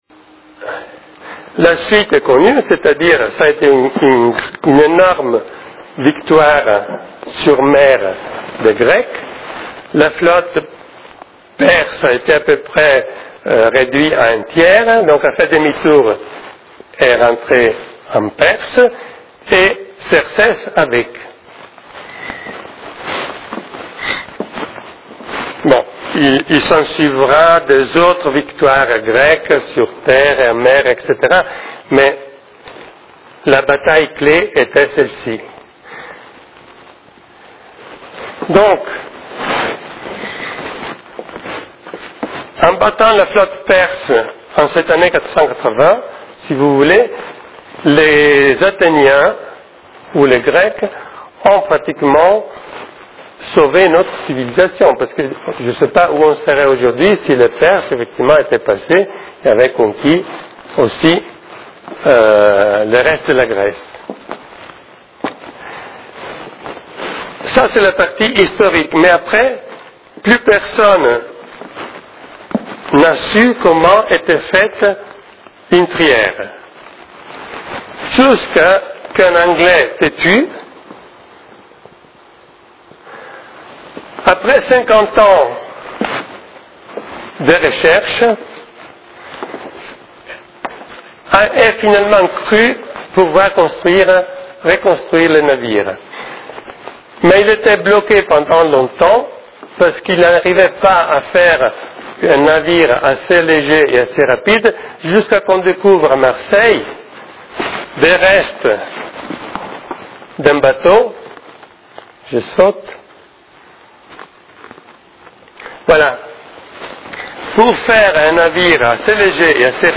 Conférence tenue à Cassis le 7.12.2004 sur :